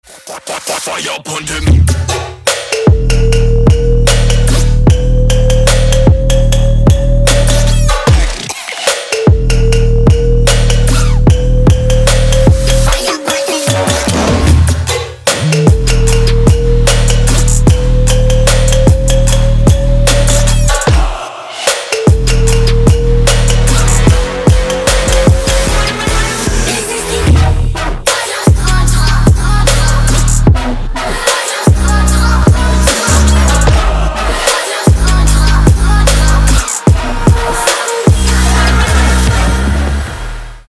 • Качество: 192, Stereo
Electronic
Trap
club
качающие
Bass